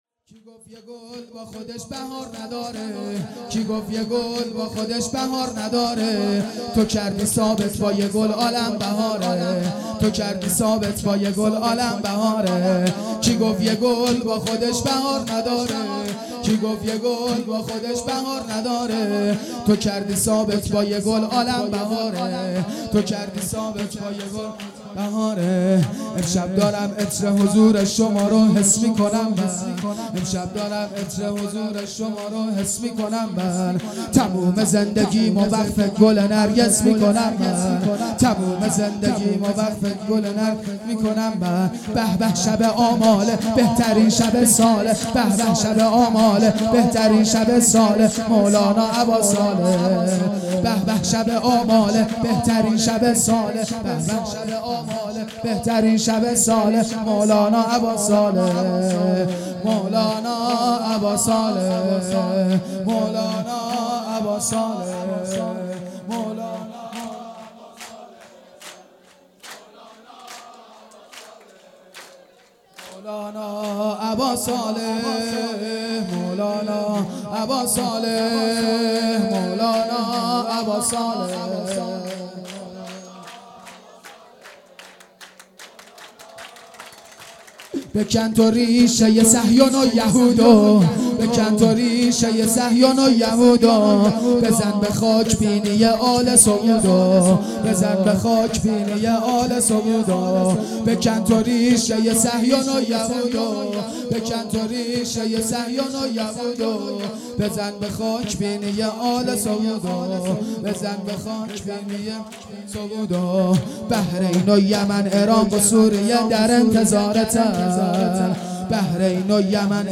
کی گفت یه گل با خودش بهار نداره _ شور
جشن ولادت امام زمان عج الله تعالی فرج الشریف